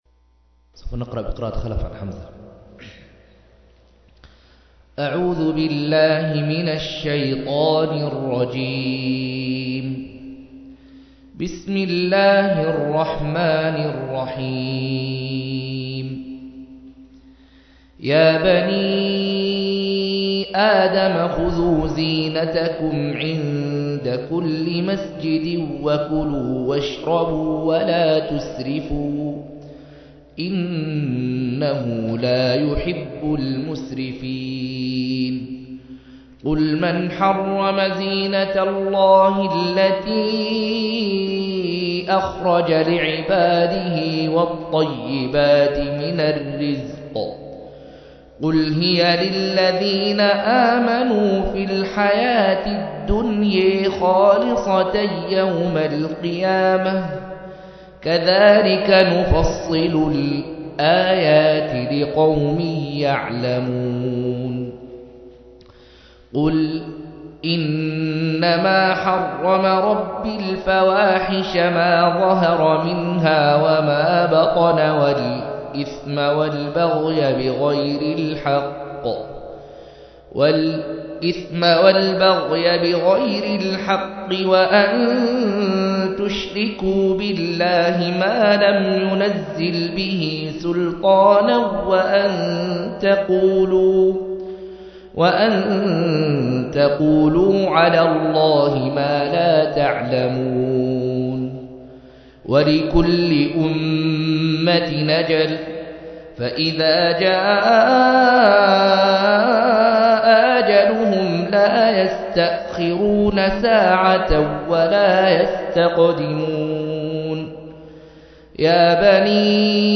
147- عمدة التفسير عن الحافظ ابن كثير رحمه الله للعلامة أحمد شاكر رحمه الله – قراءة وتعليق –